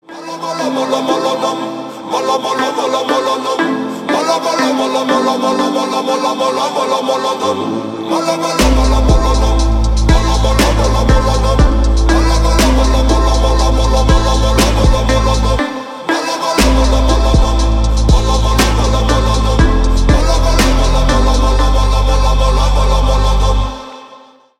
бесплатный рингтон в виде самого яркого фрагмента из песни
Поп Музыка
грустные